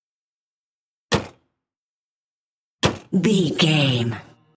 Car hood close
Car hood close 360
Sound Effects